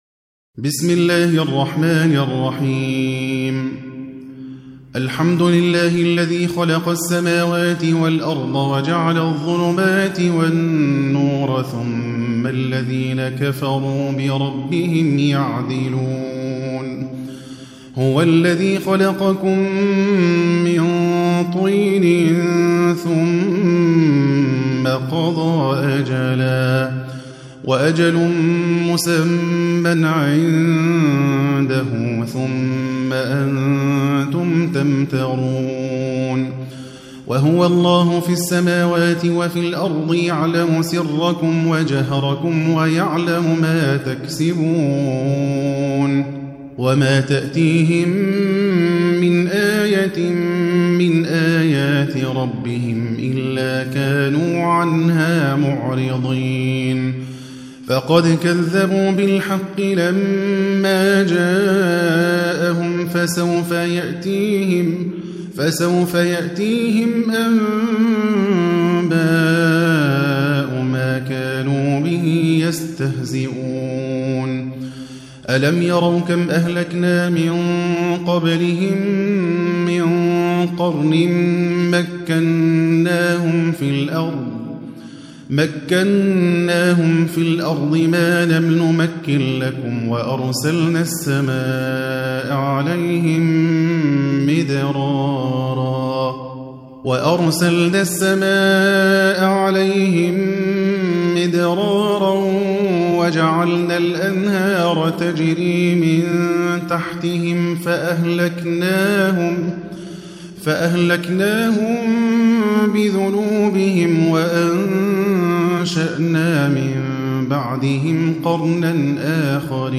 6. Surah Al-An'�m سورة الأنعام Audio Quran Tarteel Recitation
Surah Repeating تكرار السورة Download Surah حمّل السورة Reciting Murattalah Audio for 6.